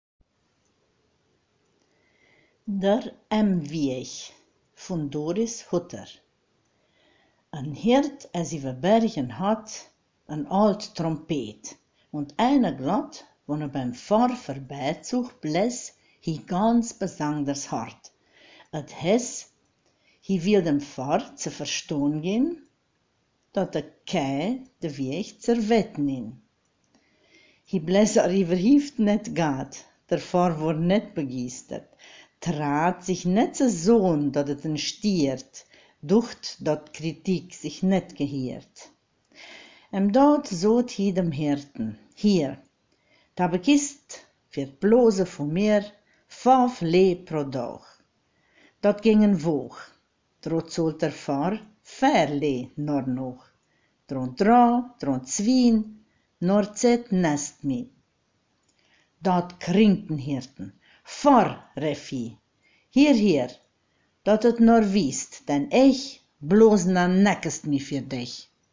Sachsesch Wält